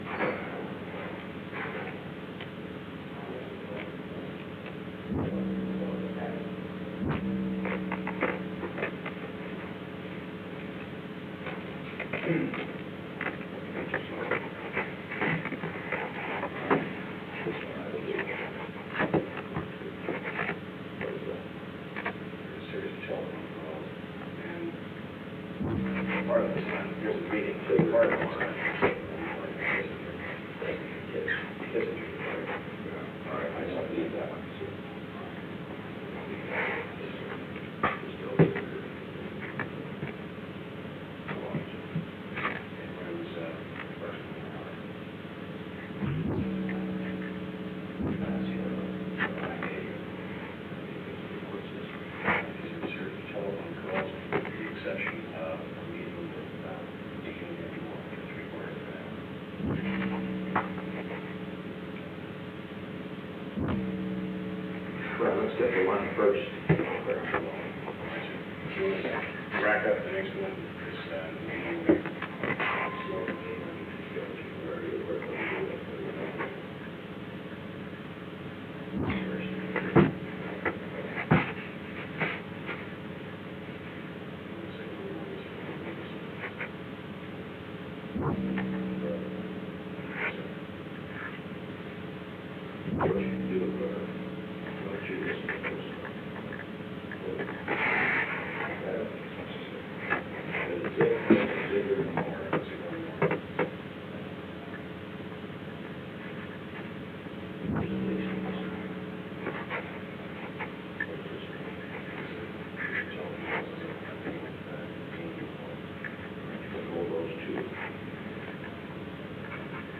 Secret White House Tapes
Conversation No. 442-36
Location: Executive Office Building